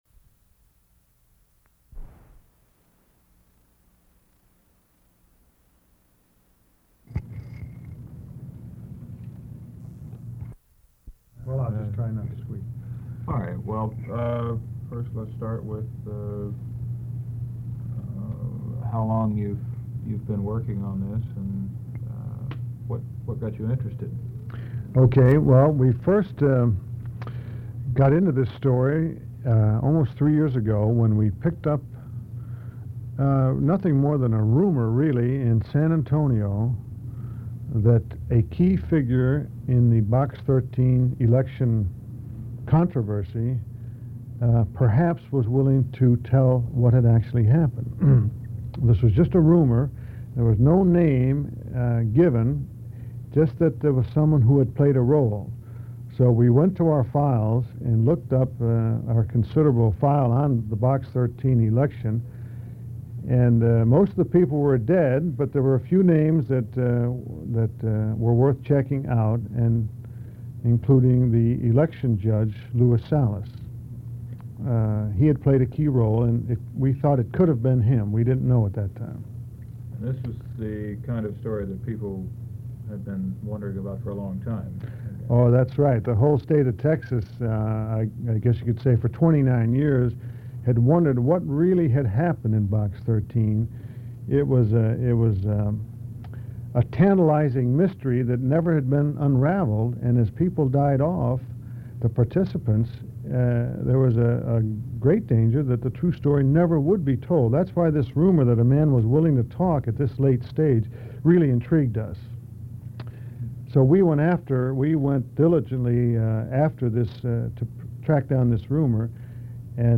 Ends very abruptly
Format Audio tape
Specific Item Type Interview Subject Congressional Elections Texas